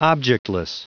Prononciation du mot objectless en anglais (fichier audio)
Prononciation du mot : objectless